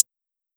Hi-Hat (Use This Gospel).wav